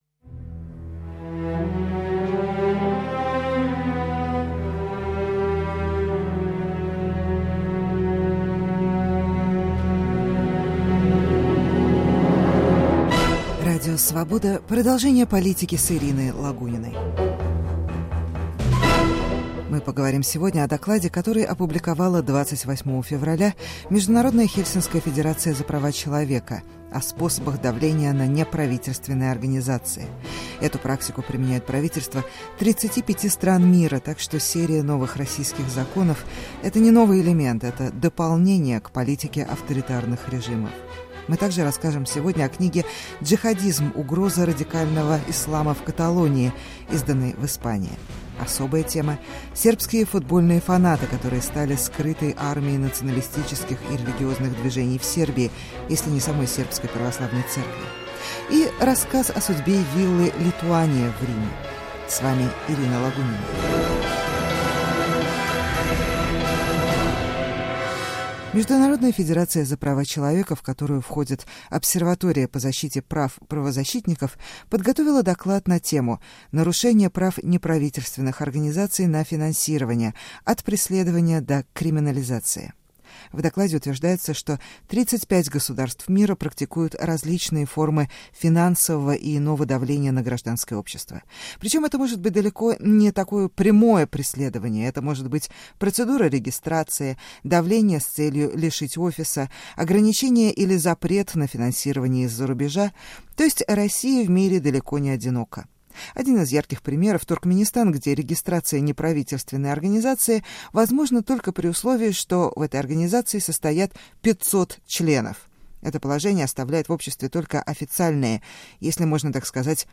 Круглый стол с участием юриста Интигама Алиева